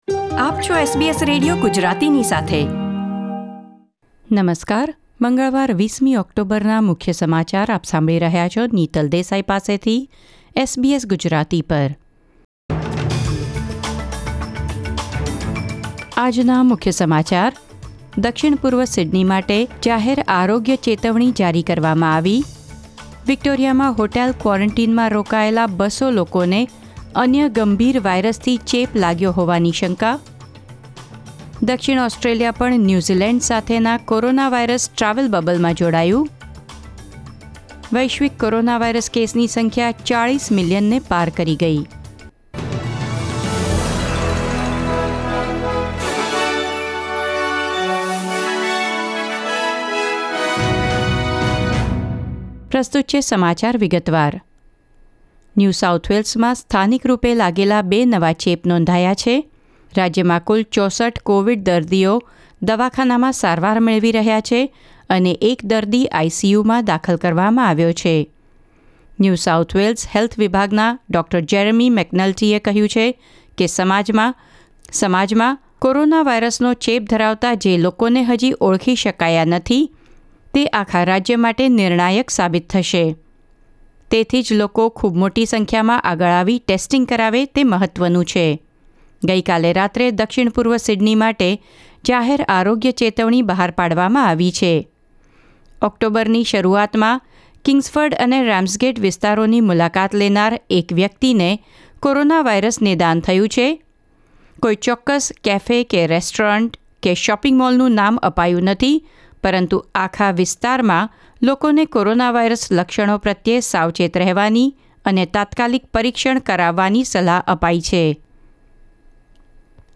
SBS Gujarati News Bulletin 20 October 2020